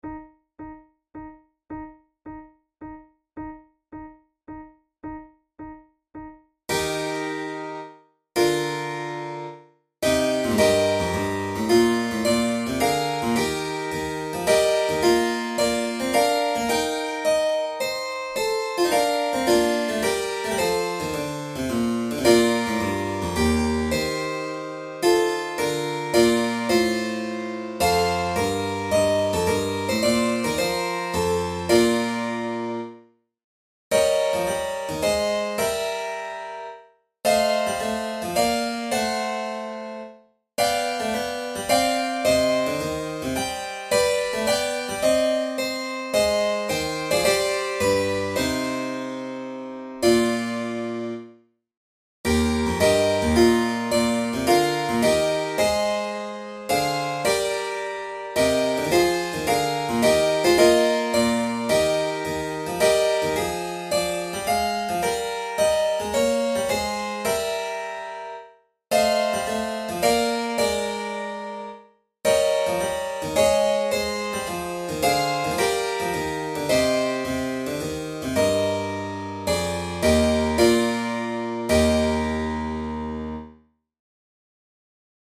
from Partita No. 6, arranged for flute and keyboard
Categories: Baroque Chaconnes Difficulty: intermediate
telemann-partita-no6-iv-tempo-di-ciaconna-1.mp3